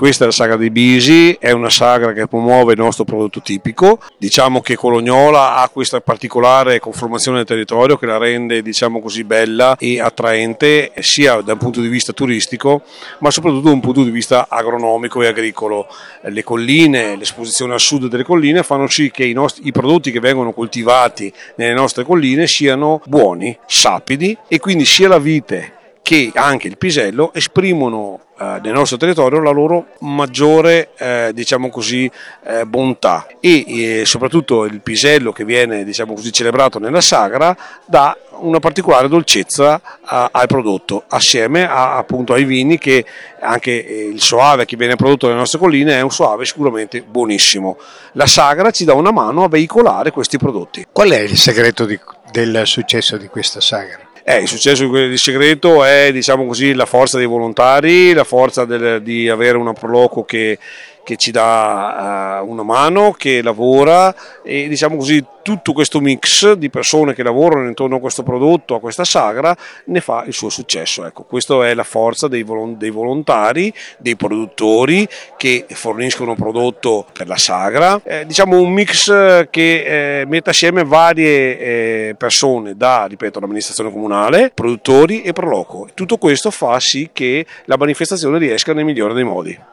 Andrea Nogara, assessore all’Agricoltura del comune di Colognola ai Colli